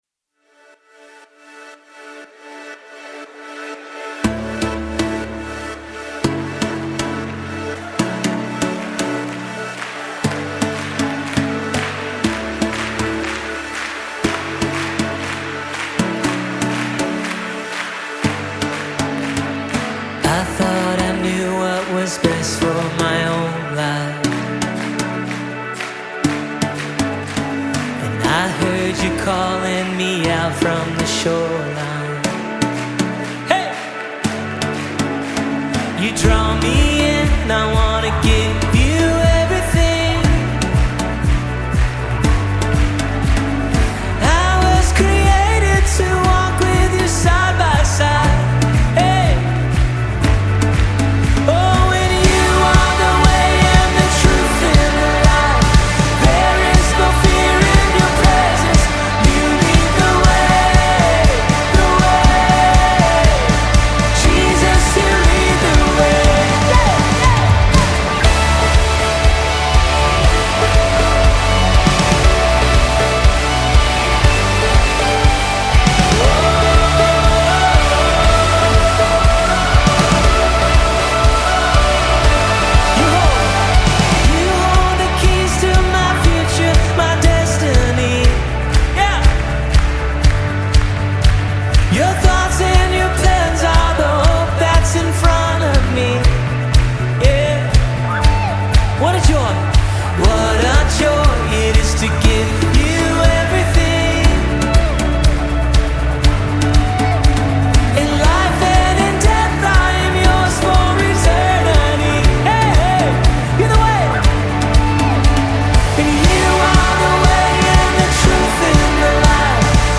distinct, ethereal vocals carry the weight of the lyrics